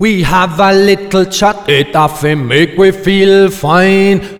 OLDRAGGA2 -R.wav